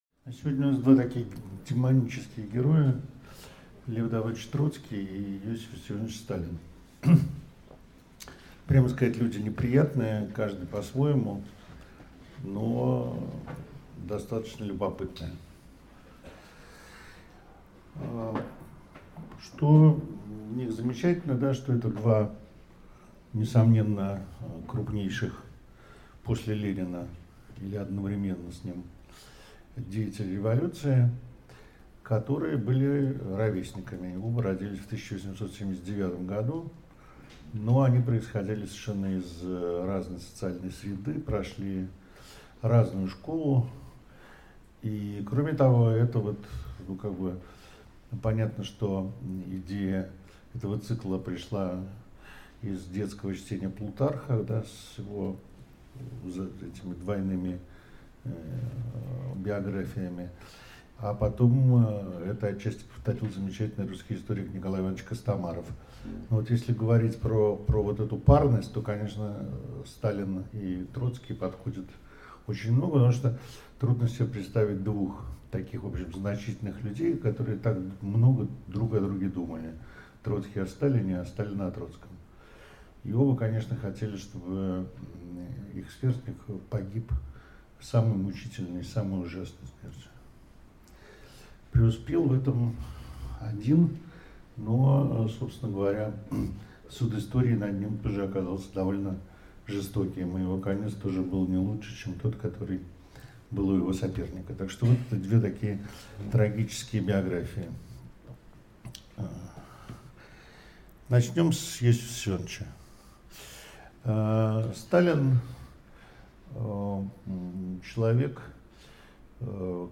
Аудиокнига Троцкий – Сталин. Россия. XX век в параллельных жизнеописаниях важнейших деятелей | Библиотека аудиокниг
Прослушать и бесплатно скачать фрагмент аудиокниги